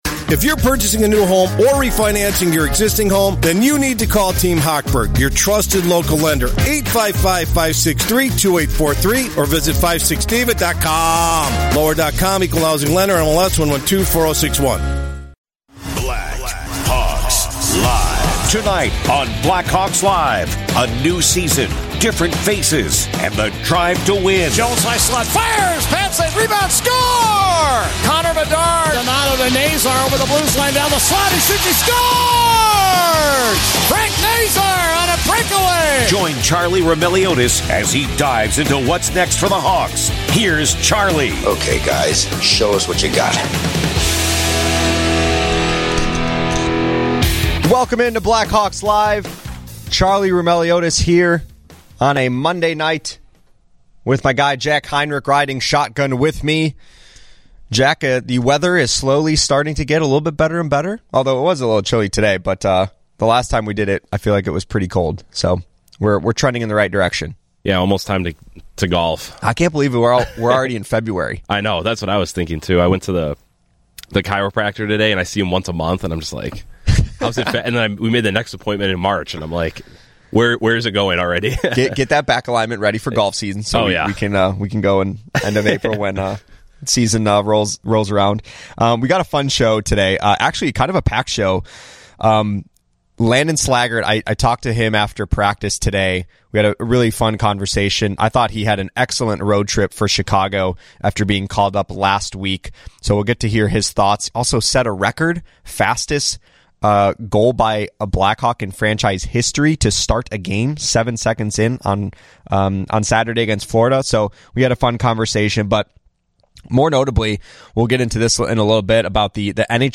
Plus, an interview with Blackhawks forward Landon Slaggert, who made history on Saturday by scoring the fastest goal to start a game in franchise history.